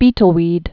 (bētl-wēd)